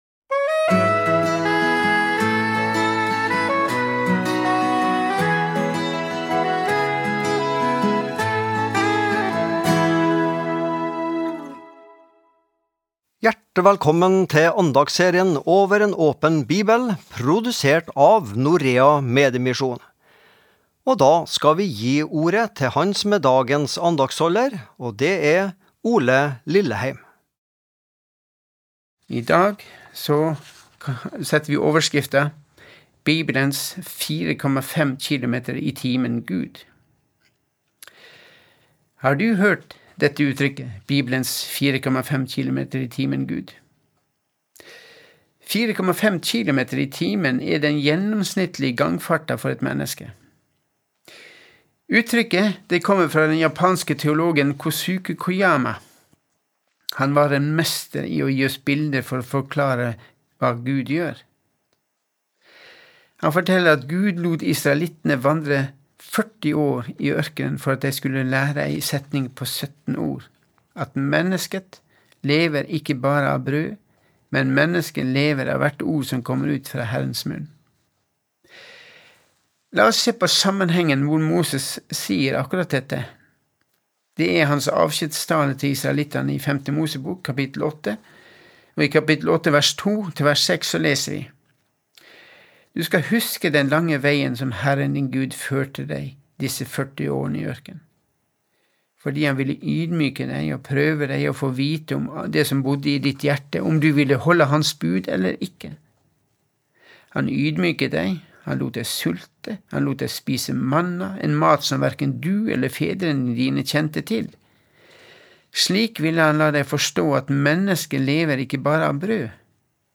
Daglige andakter (mand.-fred.). Forskjellige andaktsholdere har en uke hver, der tema kan variere.